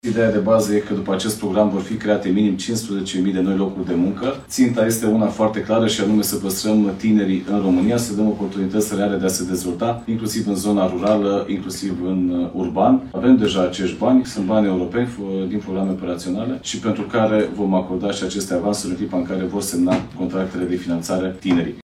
15apr-15-Bogdan-Ivan-Start-Up-Nation-editia-4.mp3